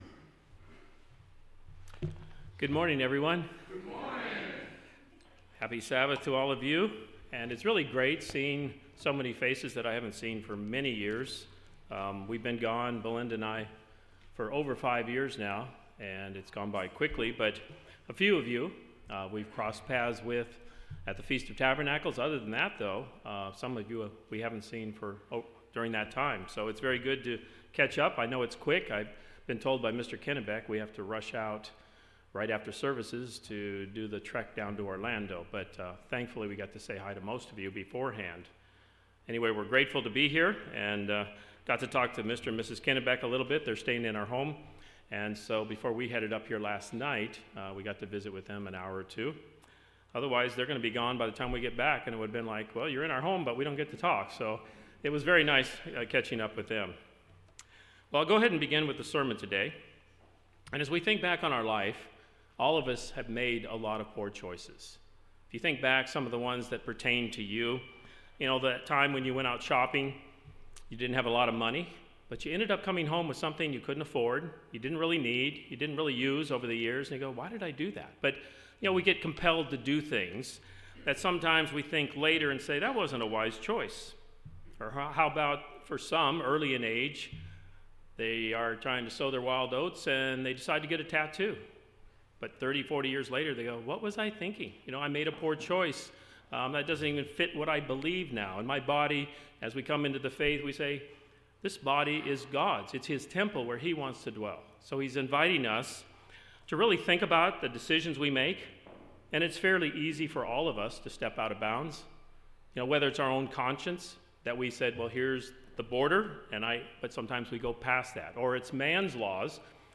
Sermons
Given in Jacksonville, FL